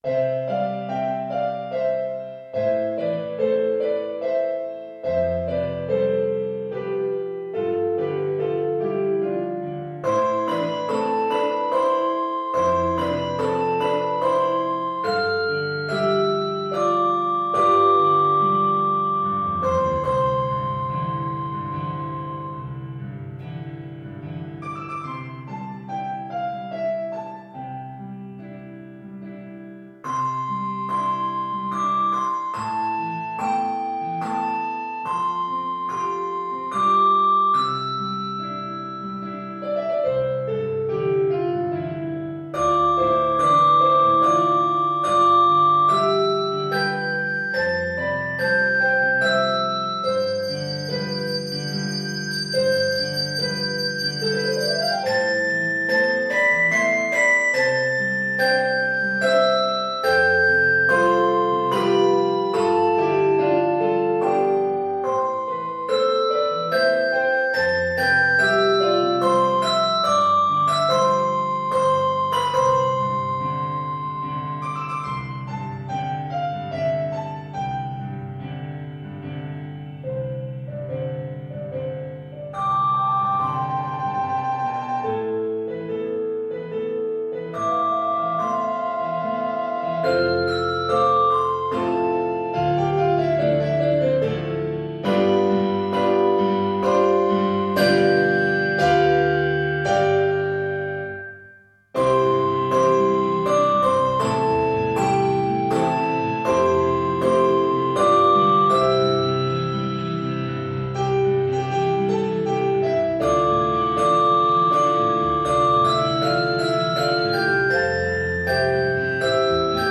Less than a full handbell choir: Solo Ringer
Technique: Sk (Shake) , tr (Trill) Voicing: Handbells
Instrument: Piano